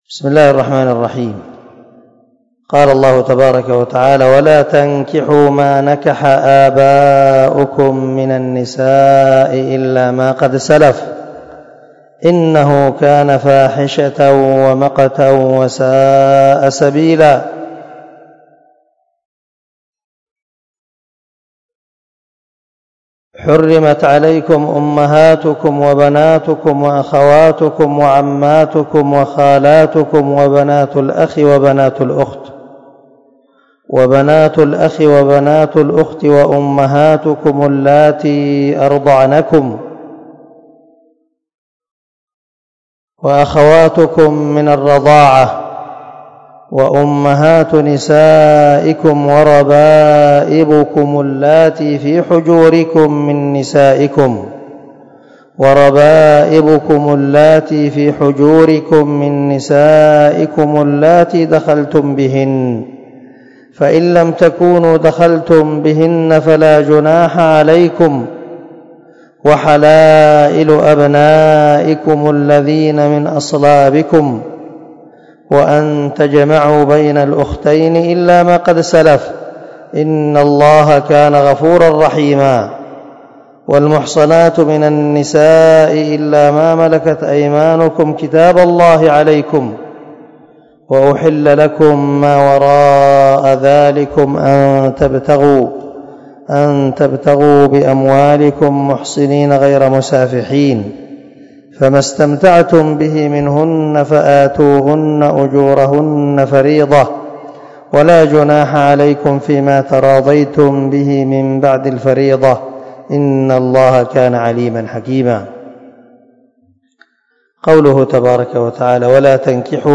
251الدرس 19 تفسير آية ( 22 – 24 ) من سورة النساء من تفسير القران الكريم مع قراءة لتفسير السعدي
دار الحديث- المَحاوِلة- الصبيحة.